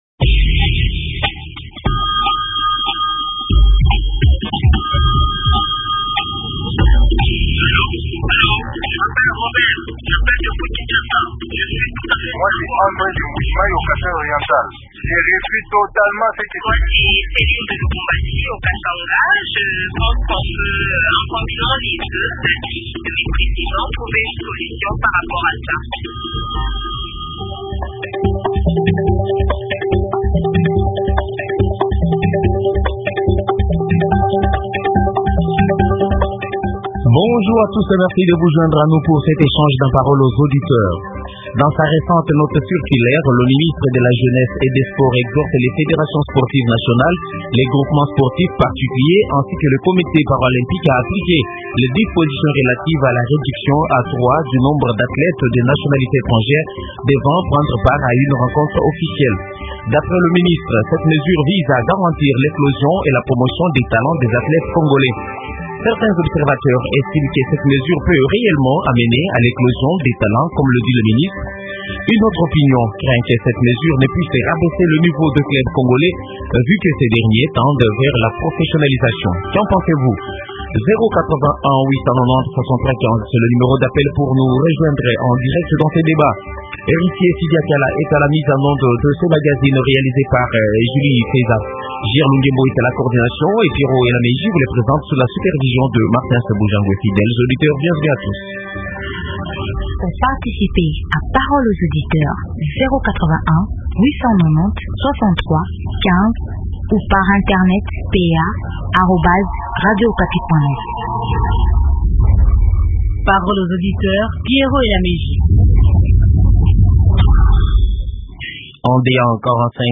Débat sur la decision de réduire à 3 le nombre d'athlètes étrangers pour des compétitions sportives